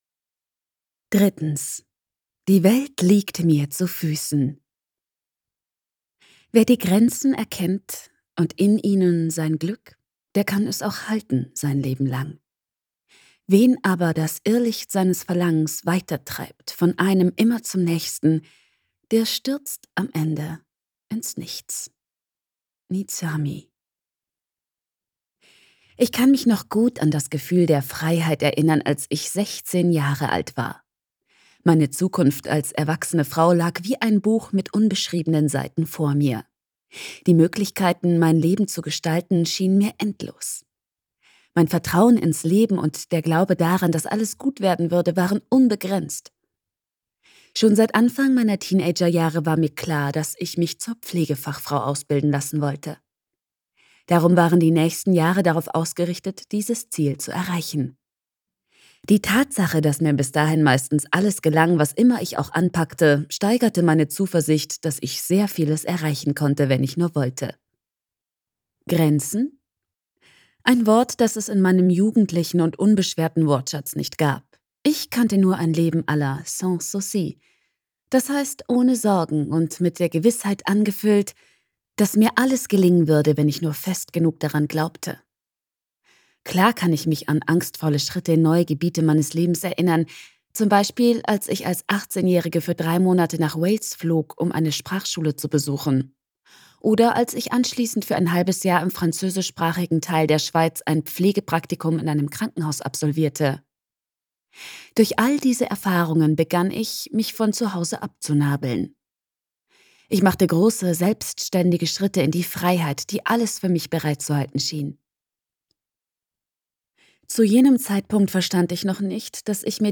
Die Welt braucht keine Superheldin - Hörbuch